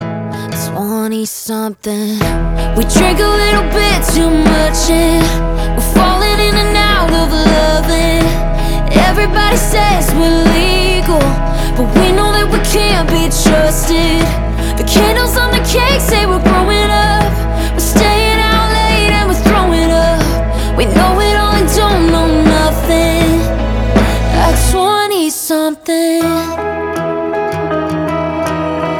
Kategorie POP